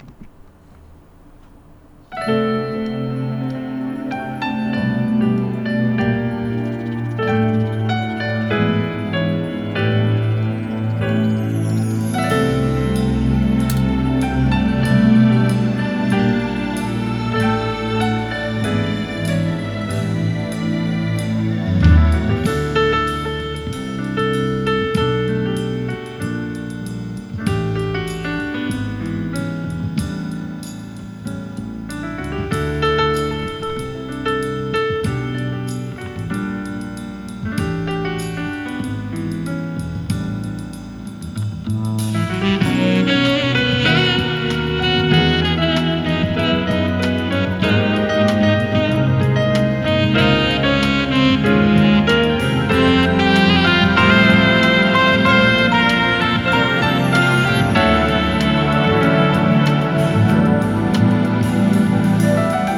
このような形でマイクで録音しながら試聴してみました。
再度セッティングして再録音です。
再録音してみましたが、やはりサブウーハの効果はバッチリです。
サブウーハ有りの場合の再生
録音した位置ではかなりサブウーハがブーミに鳴っていますが、リスニング位置（椅子に座った状態）では
ほどよい低音が聞こえます。
withSUBW.wav